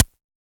RDM_TapeA_SY1-Snr03.wav